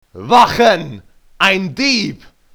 Deutsche Sprecher (m)
Selbsteinsch�tzung: m�nnlich und menschlich